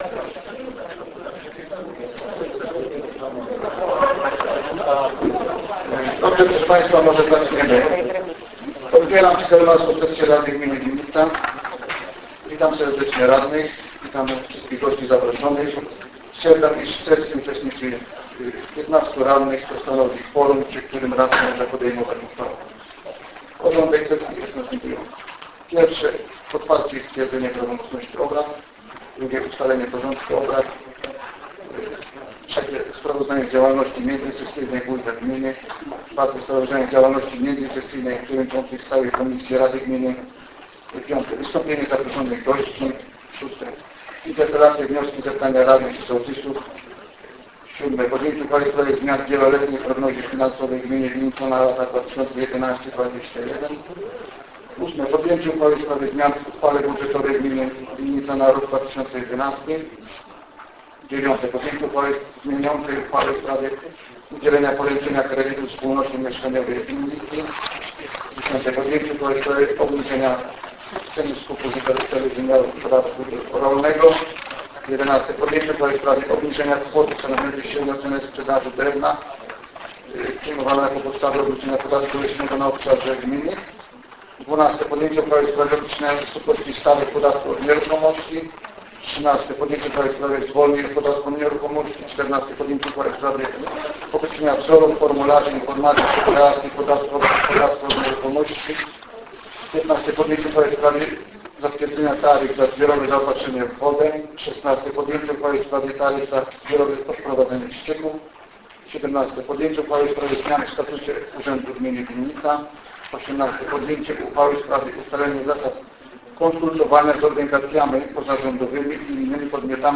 XIV Sesja Rady Gminy z dnia 30 listopada 2011 - Urząd Gminy w Winnicy
Nagranie z sesji